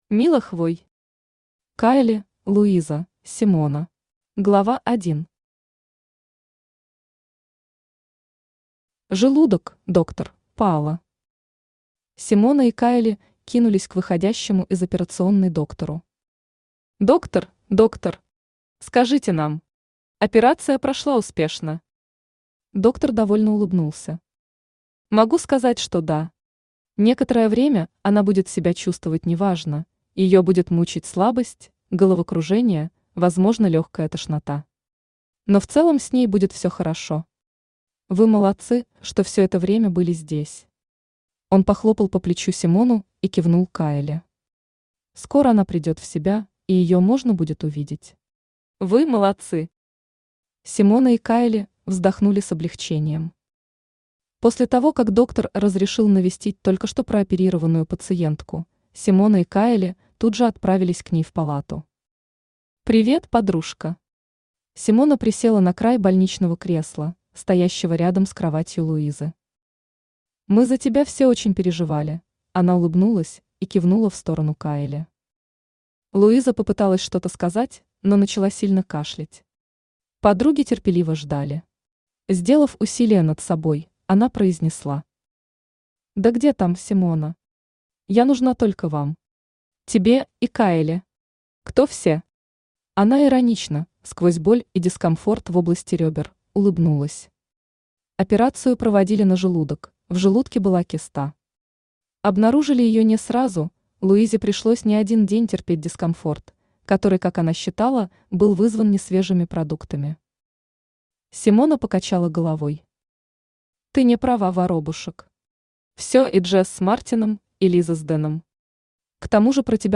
Аудиокнига Кайли, Луиза, Симона | Библиотека аудиокниг
Aудиокнига Кайли, Луиза, Симона Автор Мила Хвой Читает аудиокнигу Авточтец ЛитРес.